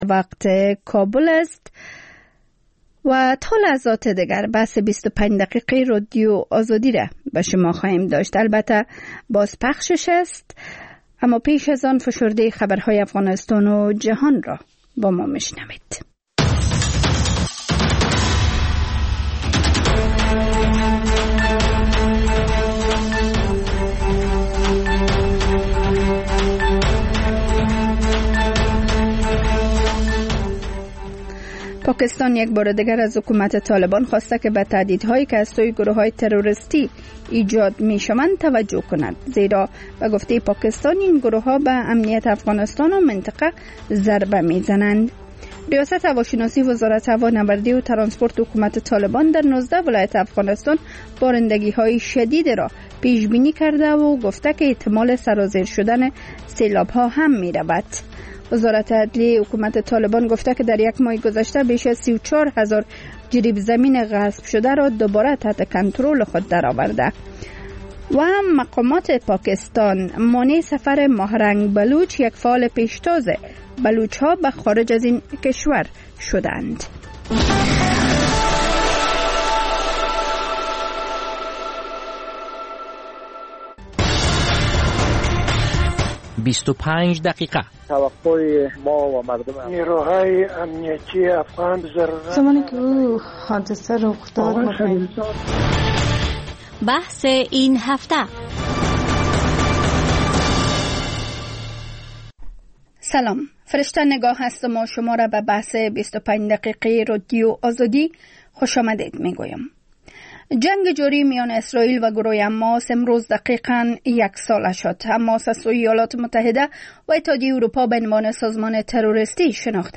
خبرهای کوتاه - میز گرد (تکرار)